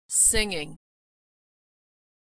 Consonant Sound Voiced /ŋ/
The /n/ is pronounced strongly. the /g/ is not pronounced fully.
ng-singing.mp3